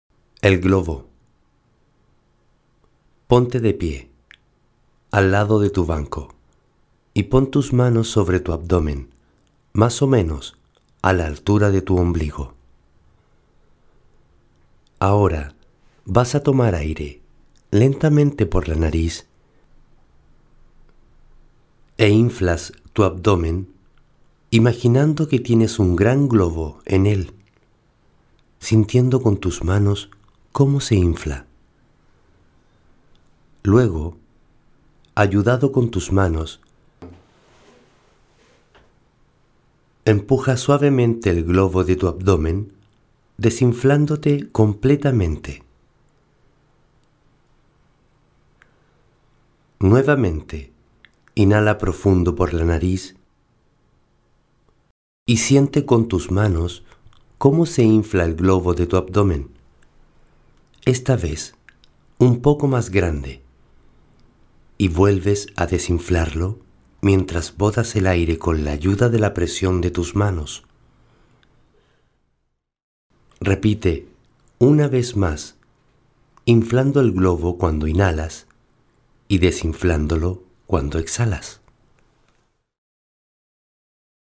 Melodía.